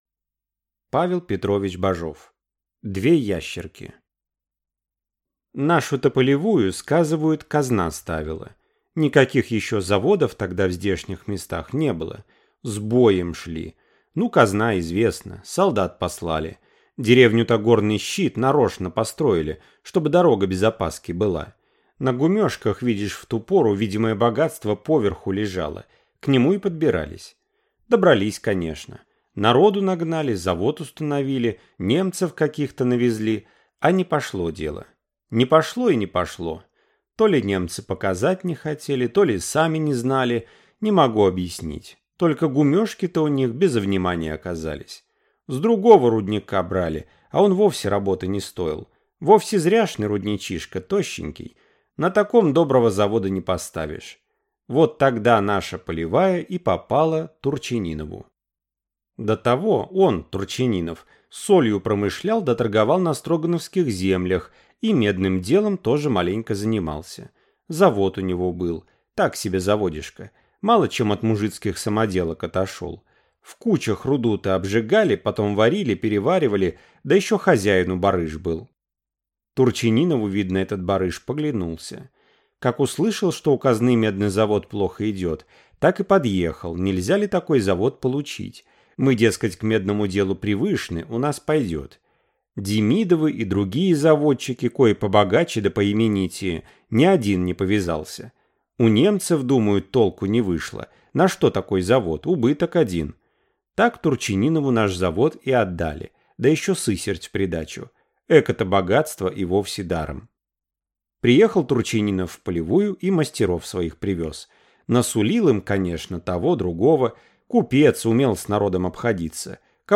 Аудиокнига Две ящерки | Библиотека аудиокниг